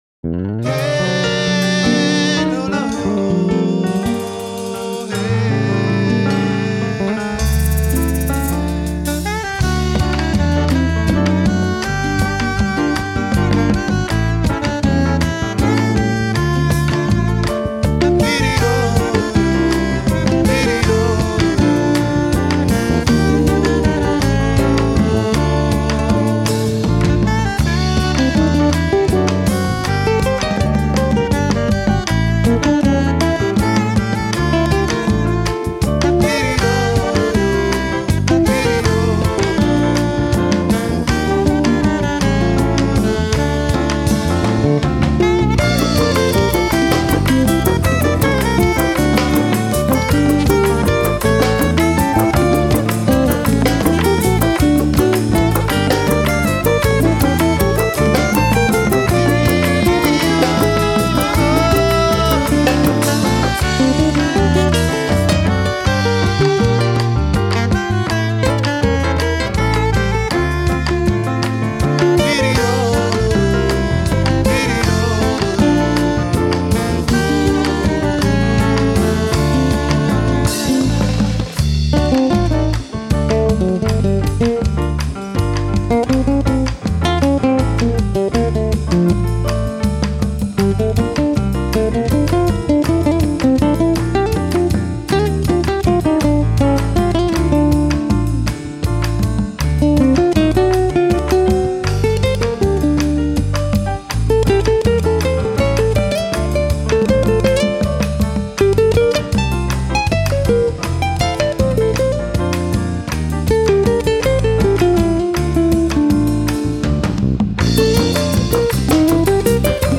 是巴西节奏和传统的拉丁美洲民间音乐的合成。
他的曲子通常明亮和厚重，节奏有力、绚丽。
拉丁爵士乐